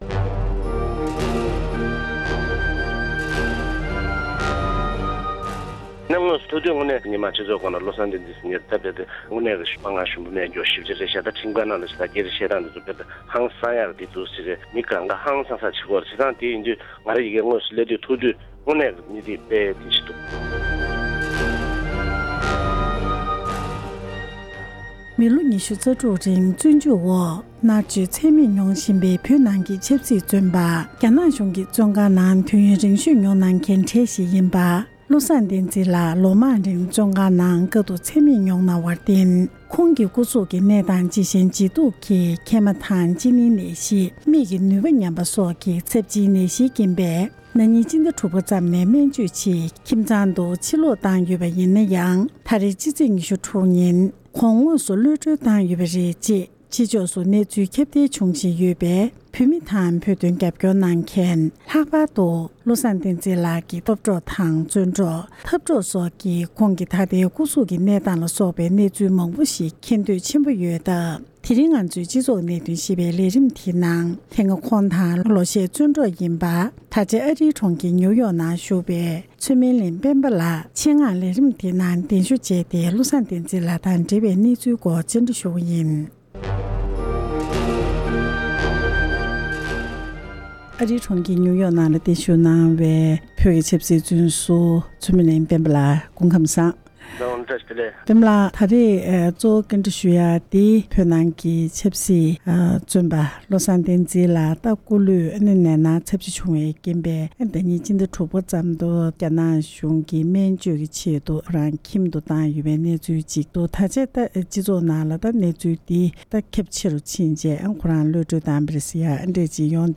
འབྲེལ་ཡོད་མི་སྣར་གནས་འདྲི་ཞུས་ཏེ